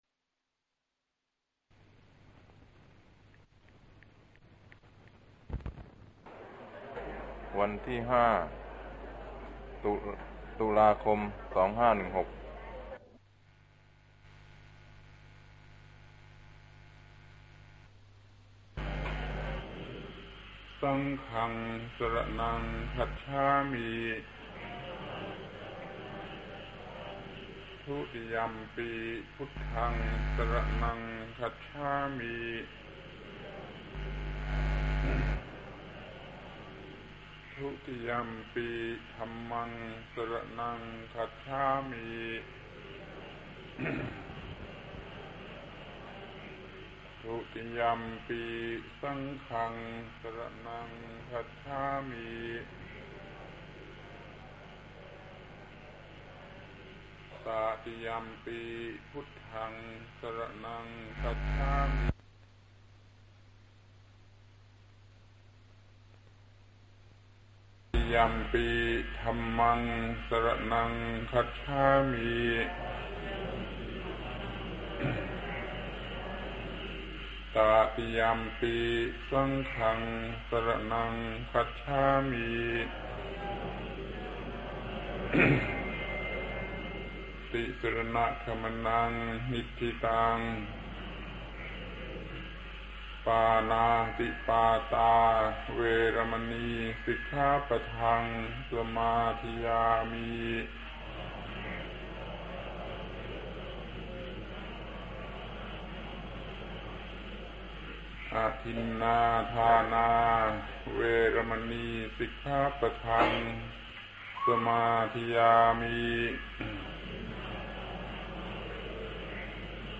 พระธรรมโกศาจารย์ (พุทธทาสภิกขุ) - เทศน์พุทธบริษัทกถา แก่คณะชาวฉวาง